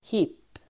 Uttal: [hip:]